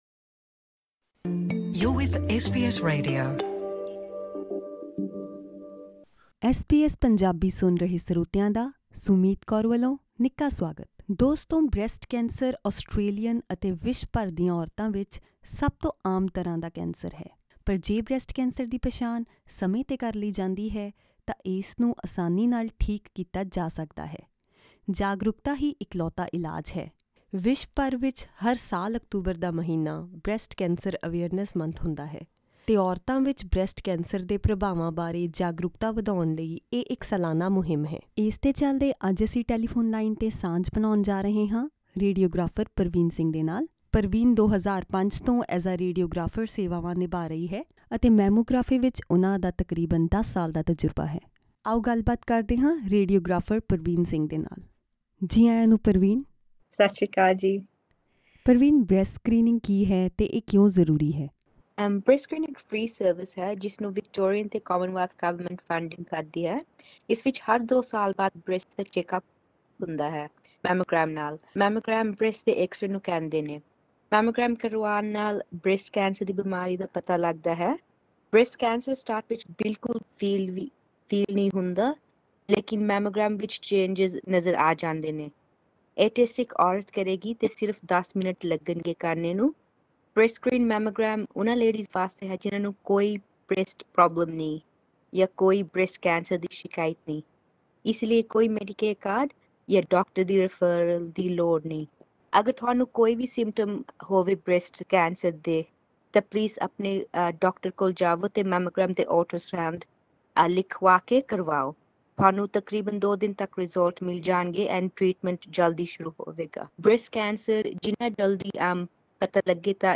ਖਾਸ ਗੱਲਬਾਤ।